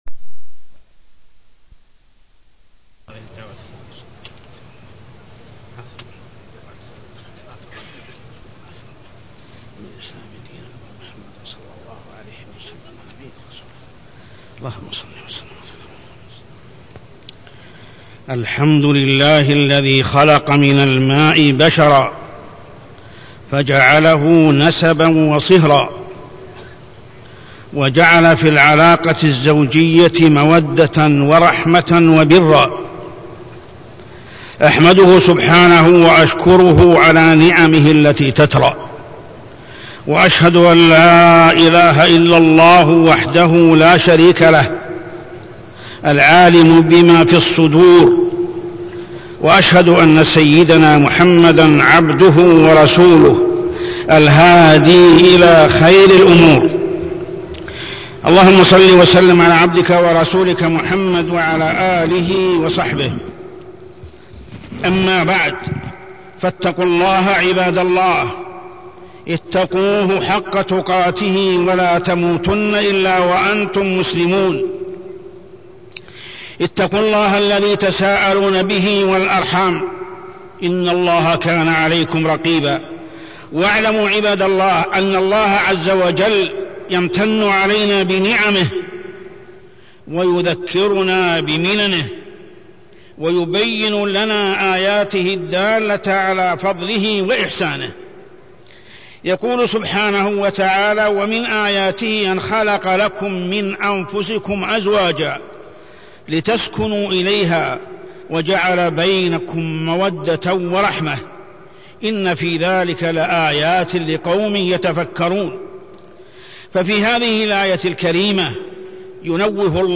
تاريخ النشر ٢٨ شعبان ١٤٢٤ هـ المكان: المسجد الحرام الشيخ: محمد بن عبد الله السبيل محمد بن عبد الله السبيل العلاقات الزوجية